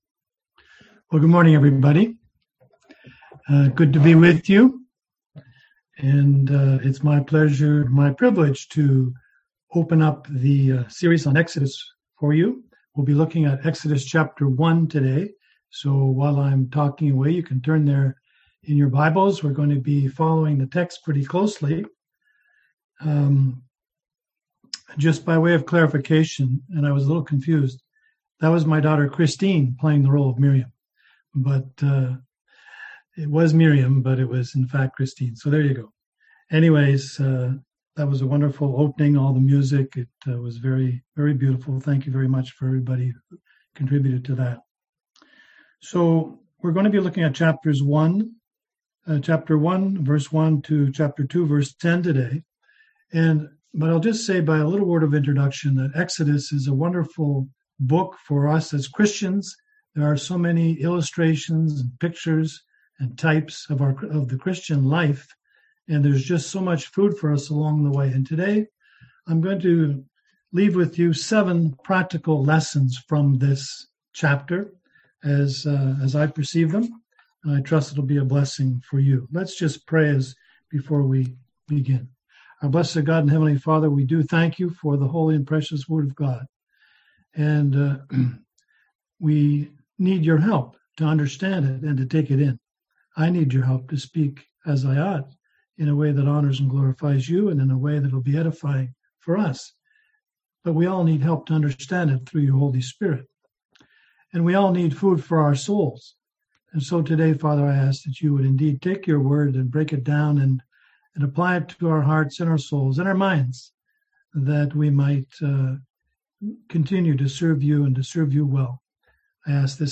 Series: Exodus 2021 Passage: Exodus 1:1-2:10 Service Type: Sunday AM